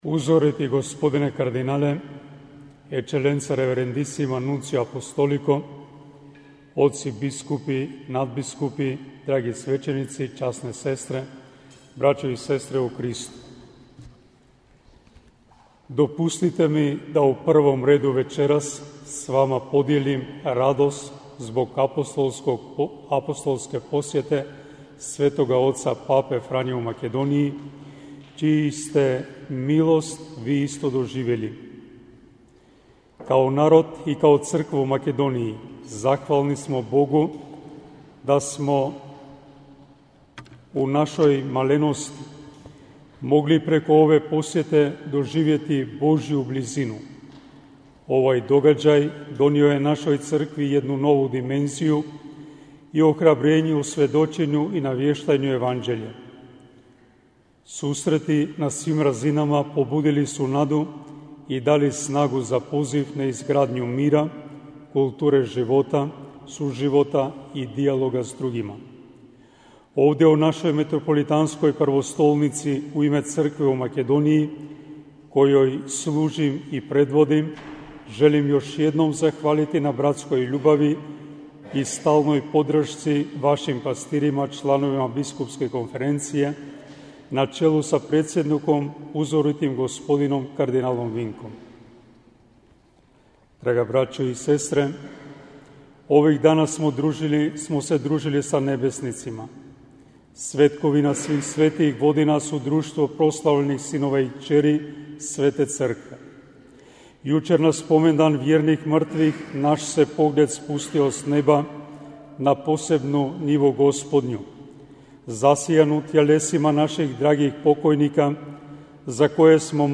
AUDIO: PROPOVIJED BISKUPA STOJANOVA NA MISI UOČI POČETKA ZASJEDANJA BISKUPSKE KONFERENCIJE BIH - BANJOLUČKA BISKUPIJA